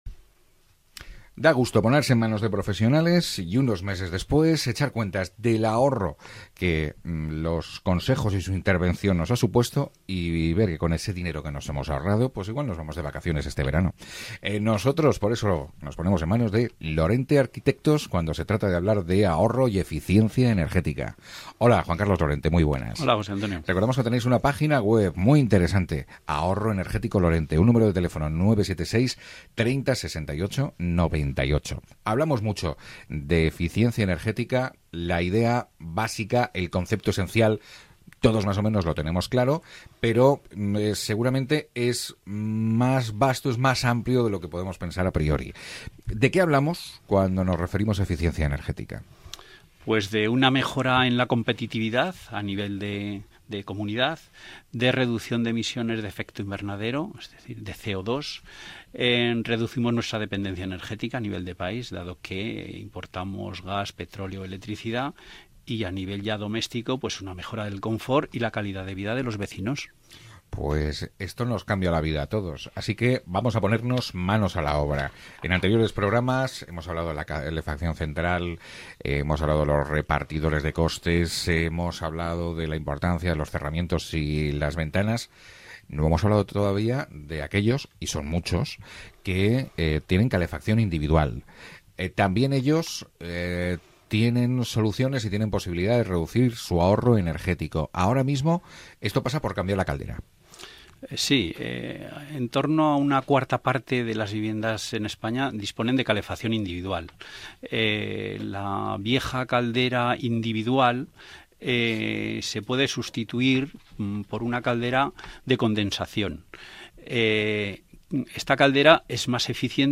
6ª Entrevista sobre eficencia energética